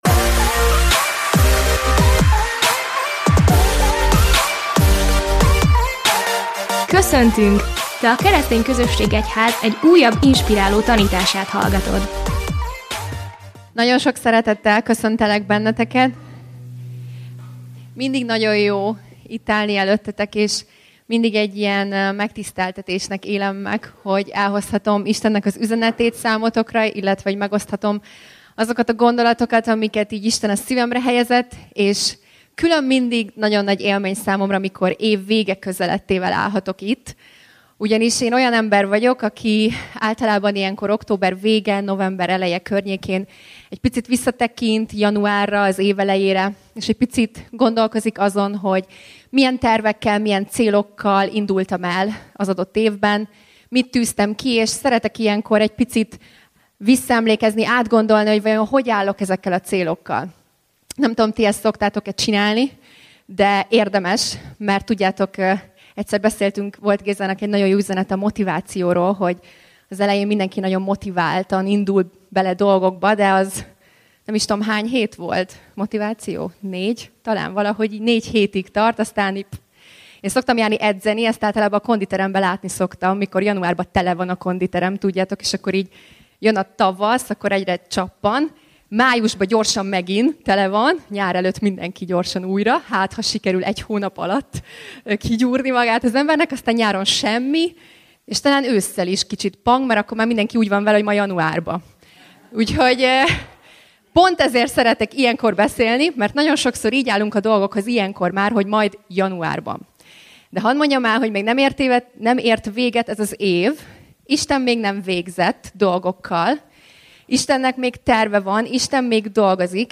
Tanítás jegyzete